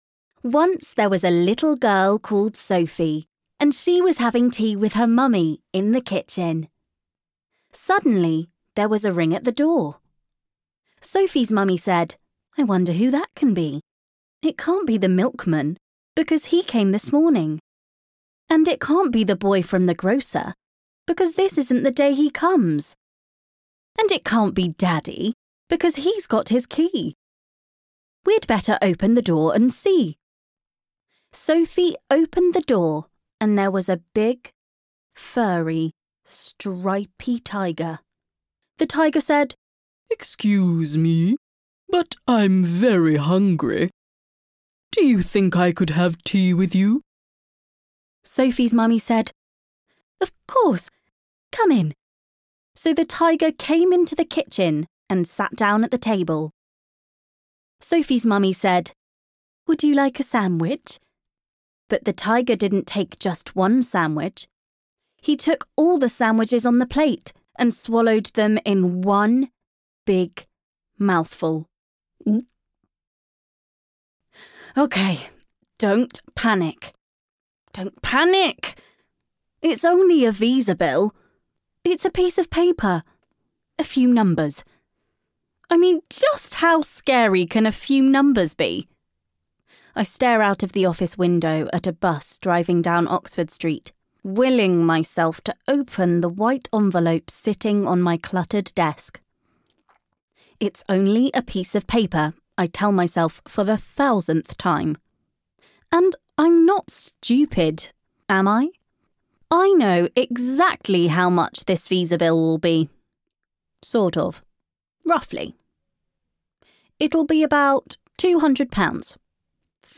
Female
My voice is warm, smooth and clear with a playing age from teens to early 30s.
Audiobook Reel
Words that describe my voice are Warm, Youthful, Clear.